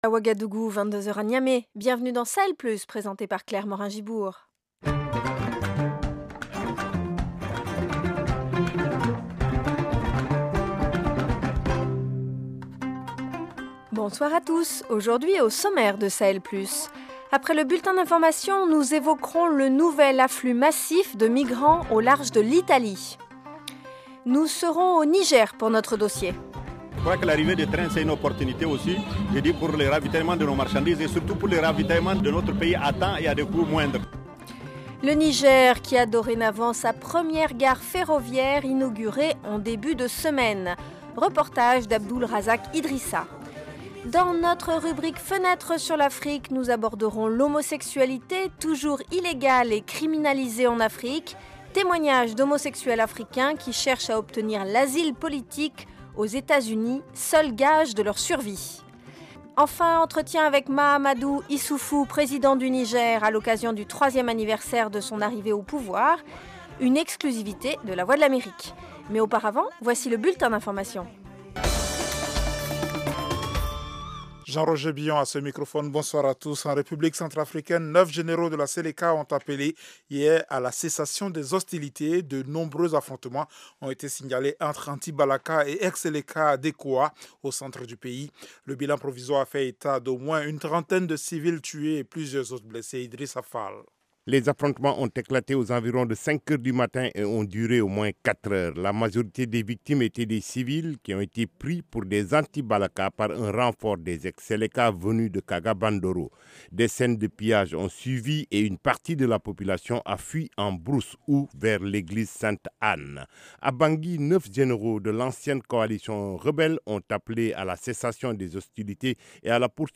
Témoignages d’homosexuels ougandais qui cherchent à obtenir l’asile politique aux Etats-Unis, seul gage de leur survie. African Voice : Mahamadou Issoufou, président du Niger à l’occasion du 3ème anniversaire de son arrivée au pouvoir.